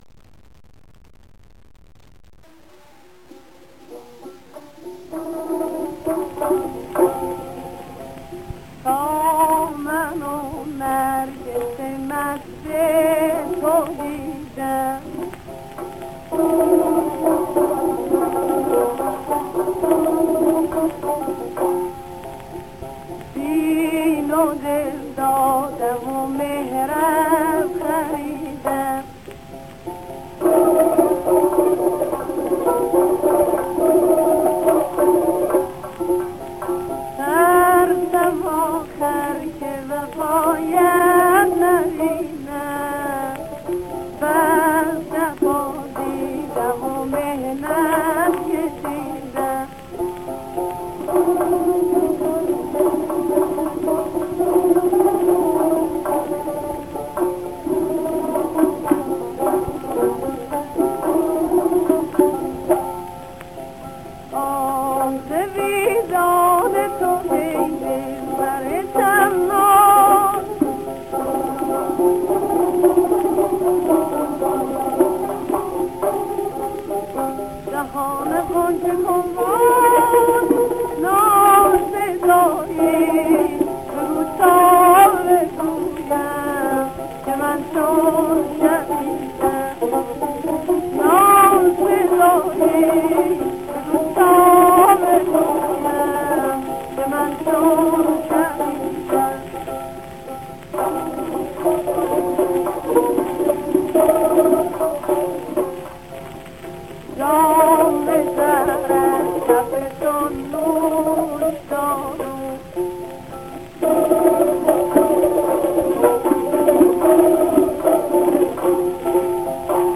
موسیقی اصیل ایران